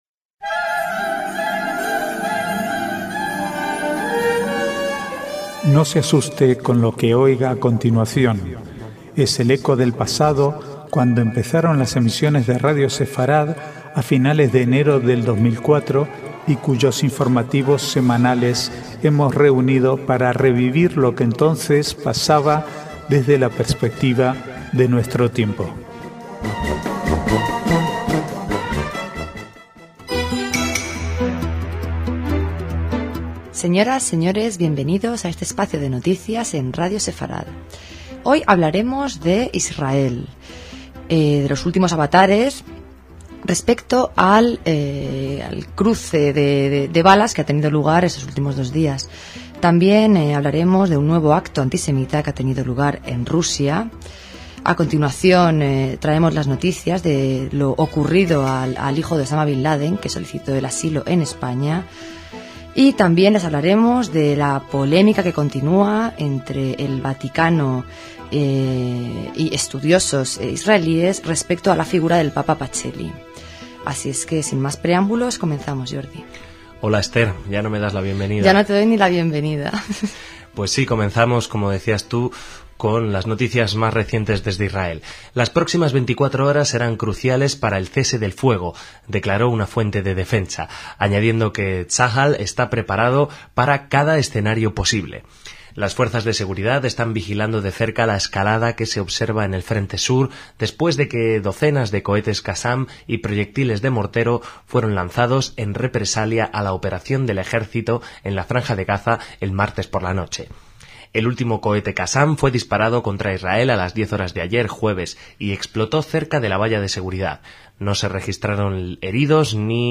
Archivo de noticias del 7 al 12/11/2008